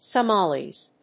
/sʌˈmɑliz(米国英語), sʌˈmɑ:li:z(英国英語)/